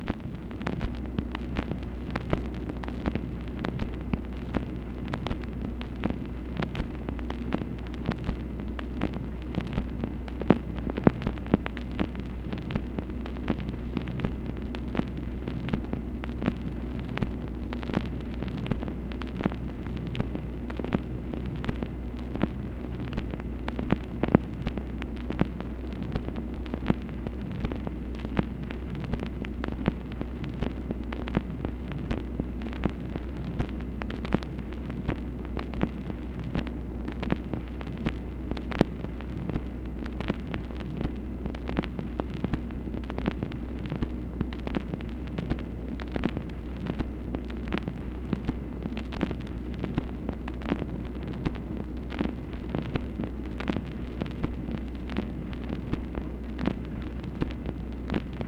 MACHINE NOISE, December 30, 1968
Secret White House Tapes | Lyndon B. Johnson Presidency